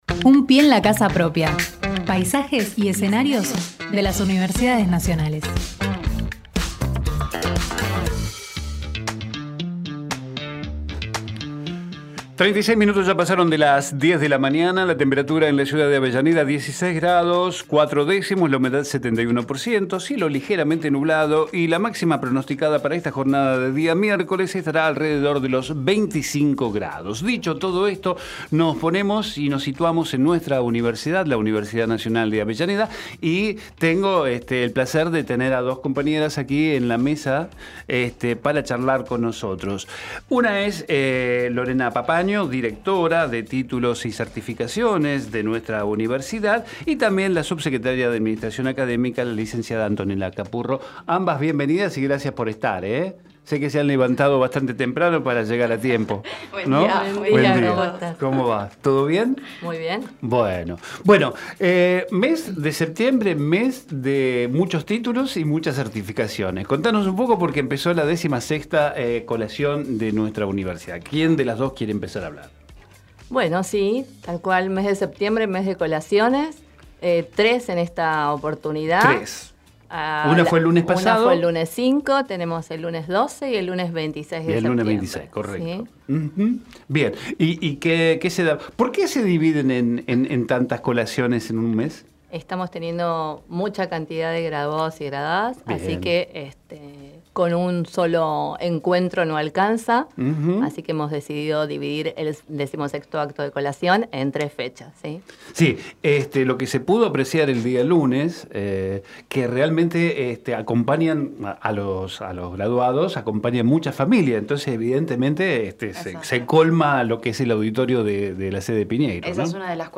Compartimos la entrevista realizada en Hacemos PyE